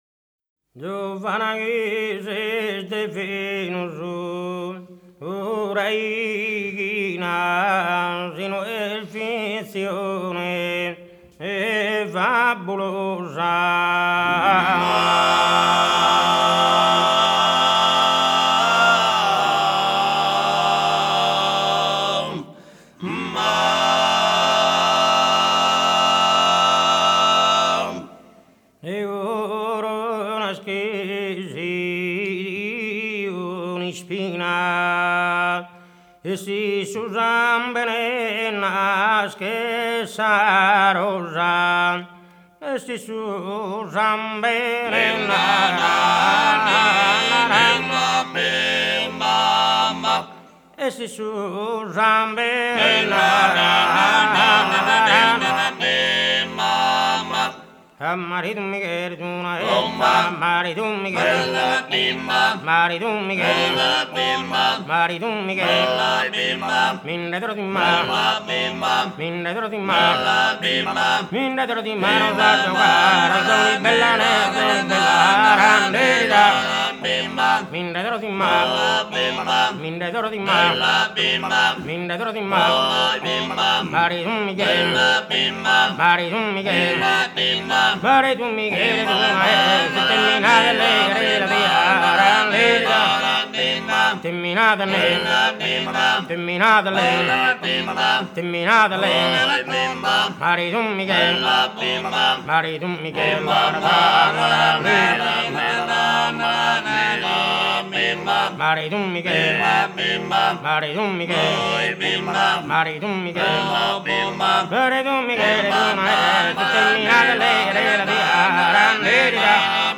Bortigali, nella regione del Marghine, è uno di quei paesi in cui si pratica ancora oggi il canto a tenore, polifonia maschile a quattro parti iscritta nelle liste UNESCO dei patrimoni immateriali dell’umanità.
poeta nato a metà del Settecento, e la boghe ’e ballu, canto con cui si accompagna la danza.
Bortigali, in the area of Marghine, is one of those villages where you can still hear “a tenore” singing, the male multipart singing included on UNESCO’s list of Intangible Cultural Heritage.
This performance proposes the two most representative genres of the Bortigali style: – the “boghe sèria”,
a poet born in the mid-eighteenth century, and “boghe ‘e ballu”, a song that is a dance accompaniment.